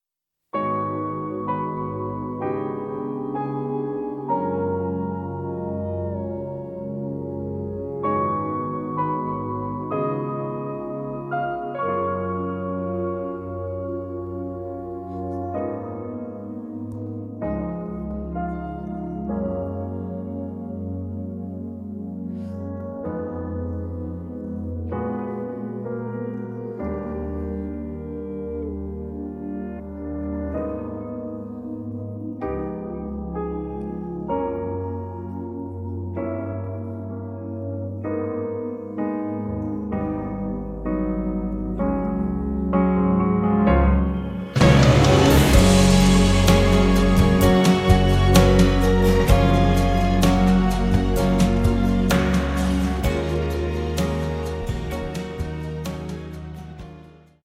음정 -1키 3:52
장르 가요 구분 Voice Cut